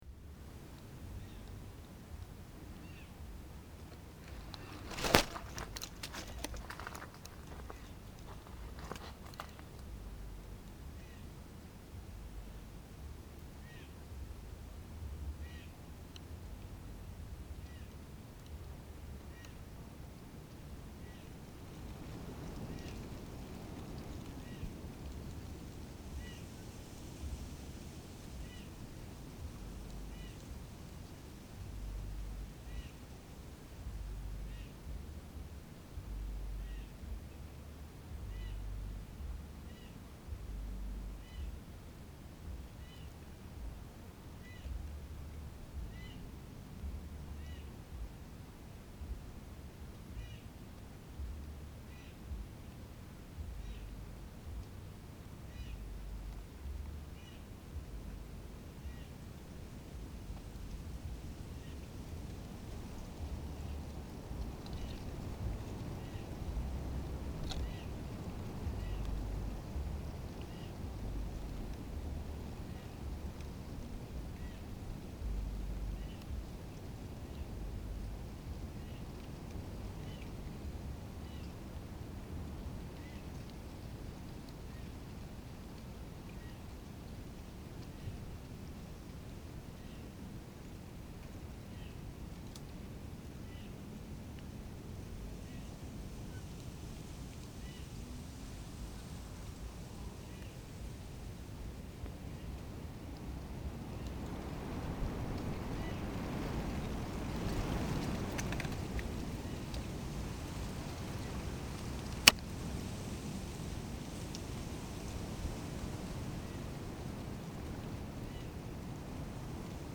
Estiaje y viento en Suchiapa
Hoy fue el primer día que desde enero no realizaba un recorrido en el campo, grabe el estiaje mezclado con el sonido del viento que amenazaba desde los cerros venir y caer con lluvia.
Lugar: Suchiapa, Chiapas; Mexico.
Equipo: Grabadora Sony ICD-UX80 Stereo, Micrófono de construcción casera ( más info ) Fecha: 2010-04-03 21:10:00 Regresar al índice principal | Acerca de Archivosonoro